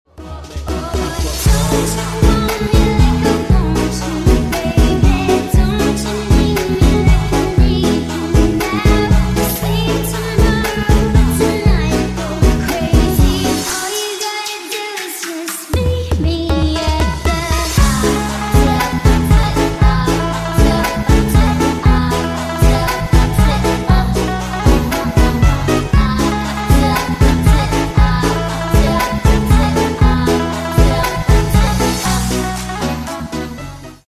suara helikopter❌ suara turbo✅ 😁 sound effects free download